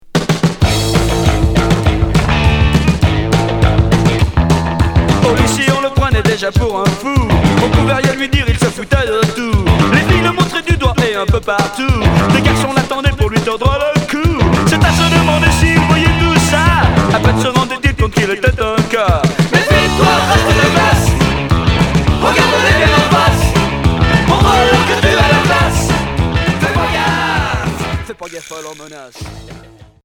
Rock Cinquième 45t retour à l'accueil